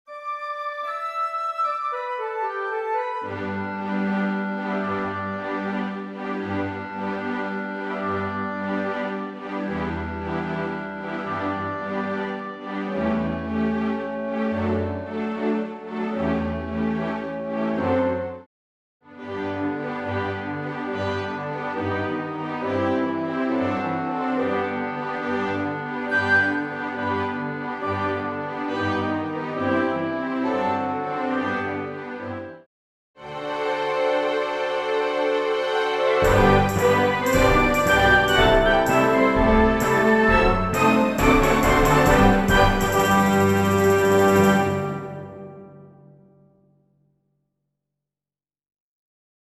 Full Orch accompaniment